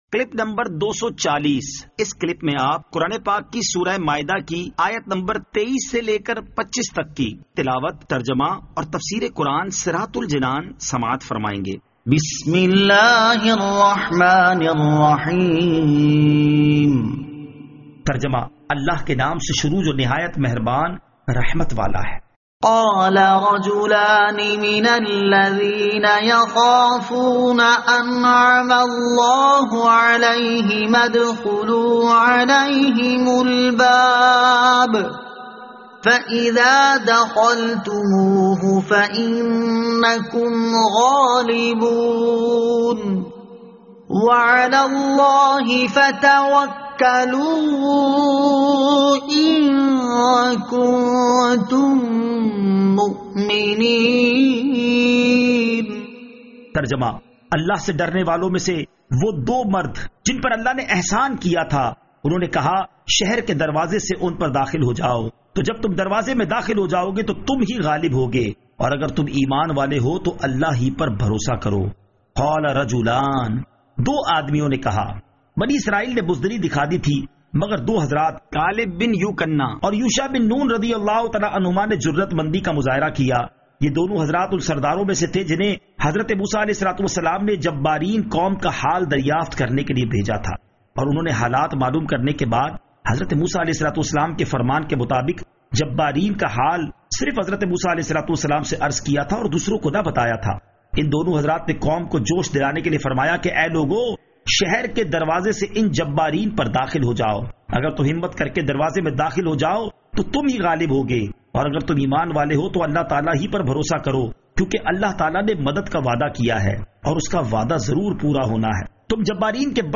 Surah Al-Maidah Ayat 23 To 25 Tilawat , Tarjama , Tafseer